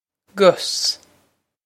‘gus g-us
This is an approximate phonetic pronunciation of the phrase.